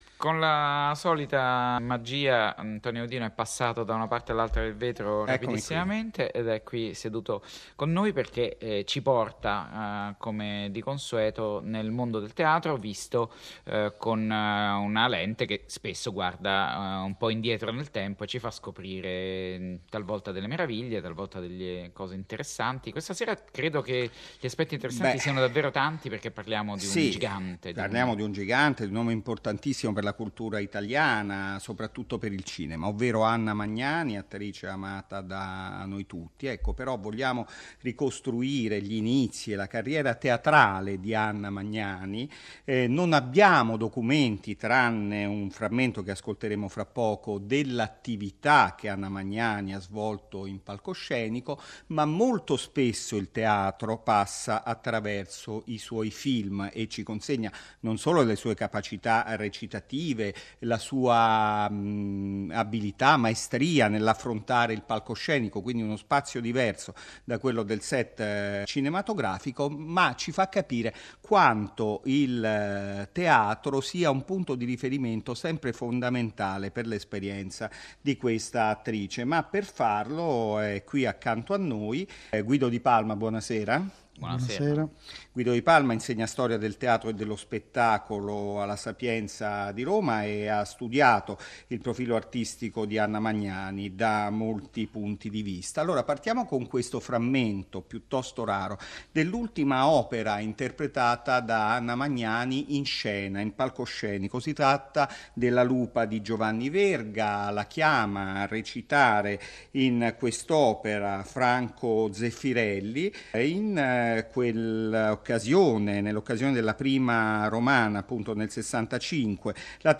Puntata Radiofonica di Rai Radio 3 del 16 giugno 2016.